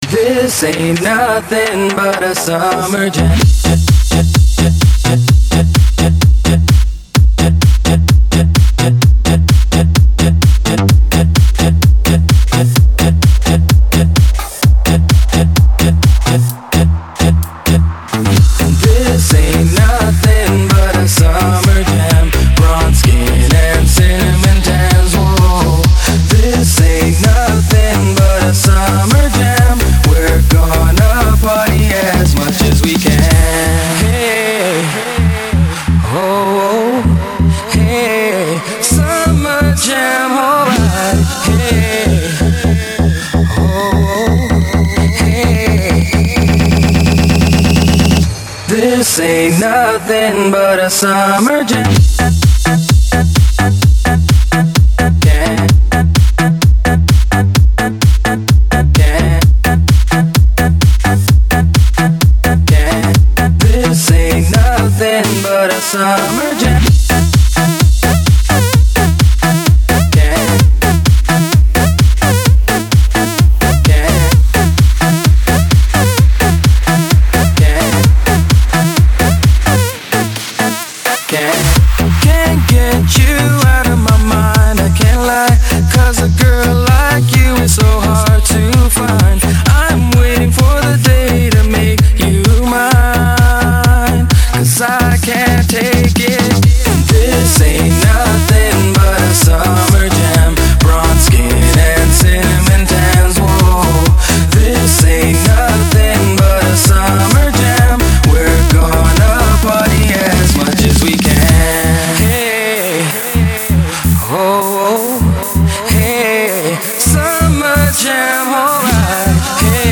Ритм отличный, ремикс на известную песенку